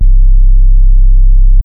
Metro Sub (808).wav